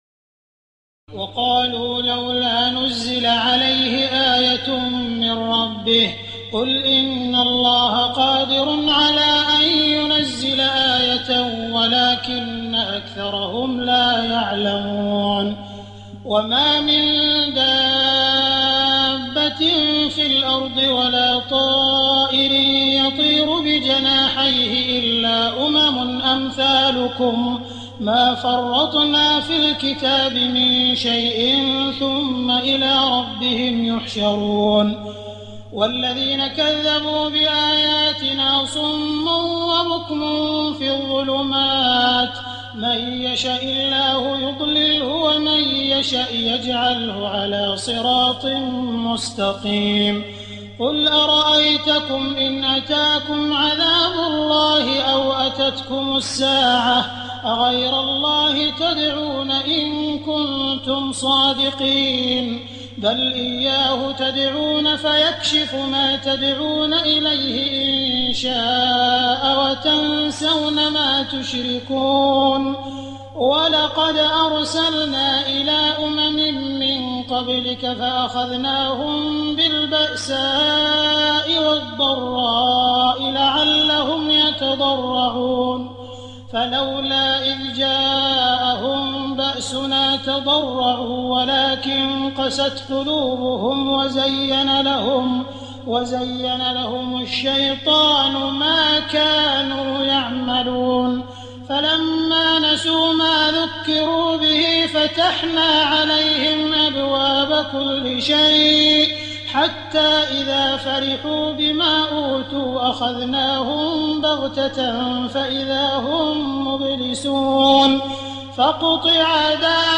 تراويح الليلة السادسة رمضان 1419هـ من سورة الأنعام (37-111) Taraweeh 6 st night Ramadan 1419H from Surah Al-An’aam > تراويح الحرم المكي عام 1419 🕋 > التراويح - تلاوات الحرمين